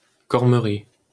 Cormery (French pronunciation: [kɔʁməʁi]